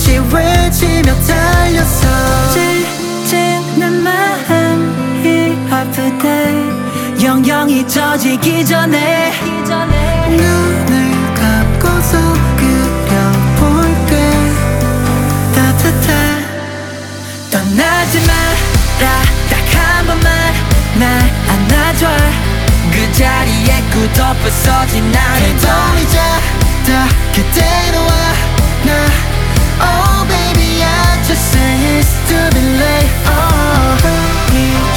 K-Pop Pop
2025-07-01 Жанр: Поп музыка Длительность